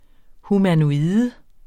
Udtale [ humanoˈiːðə ]